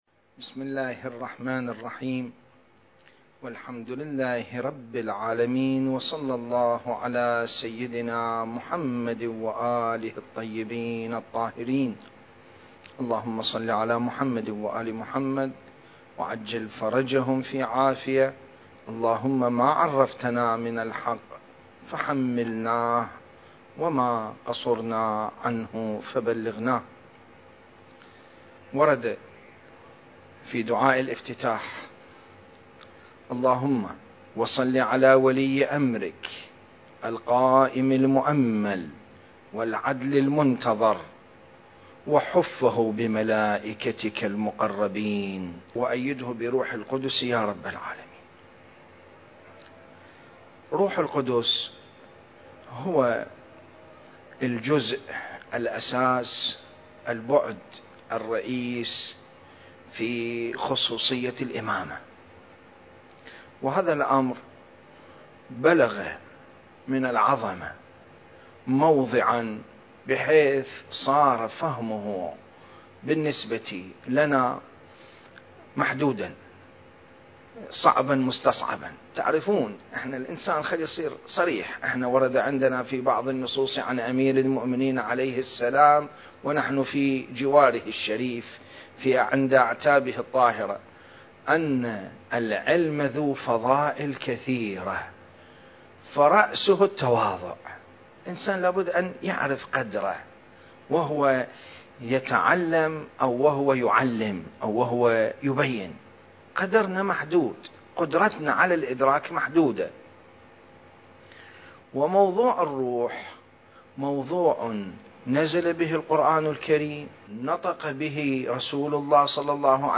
سلسلة: الإمام المهدي (عجّل الله فرجه) في دعاء الافتتاح (4) المكان: العتبة العلوية المقدسة التاريخ: 2021